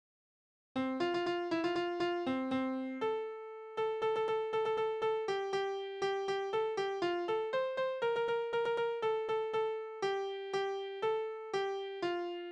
Kinderlieder: Das Bäuerlein in der Stadt
Tonart: F-Dur
Taktart: 2/4
Tonumfang: Oktave
Besetzung: vokal